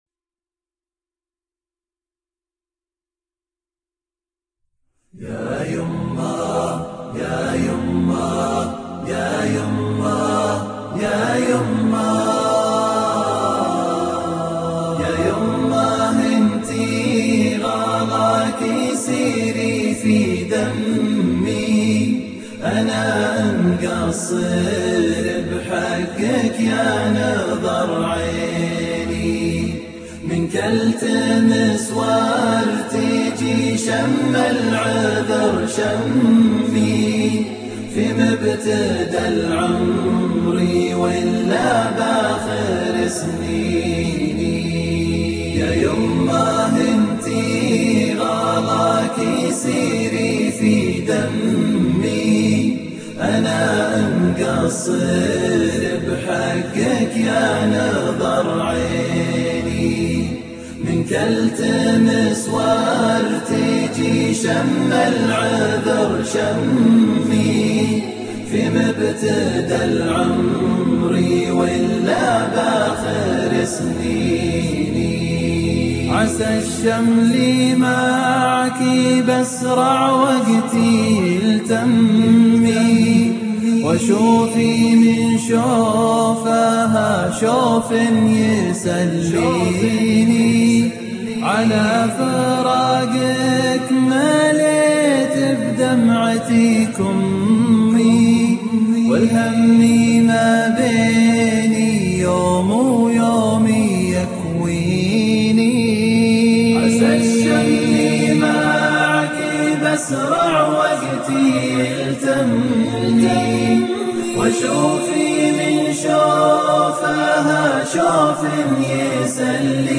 أريد نشيد